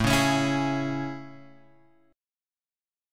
AM7 chord {5 4 x 6 5 4} chord